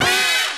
HORN HIT 5.wav